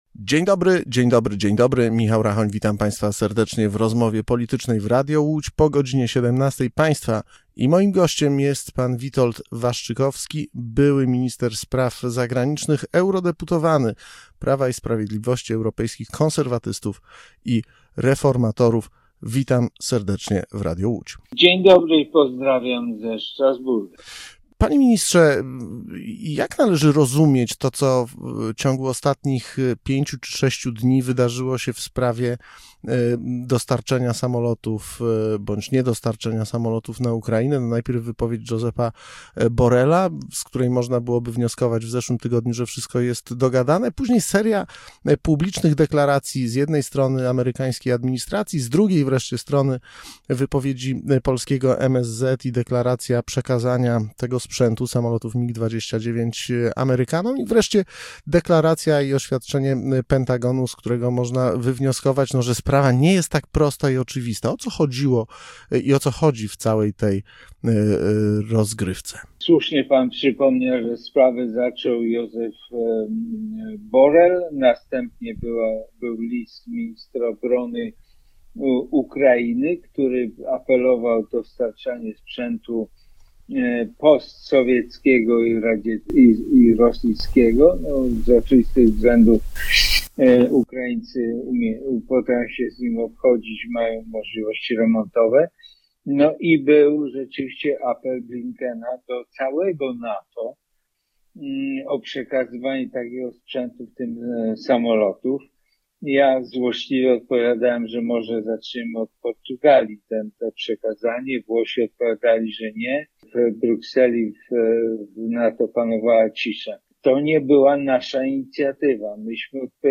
Dzisiejszym gościem po 17. był europoseł Witold Waszczykowski.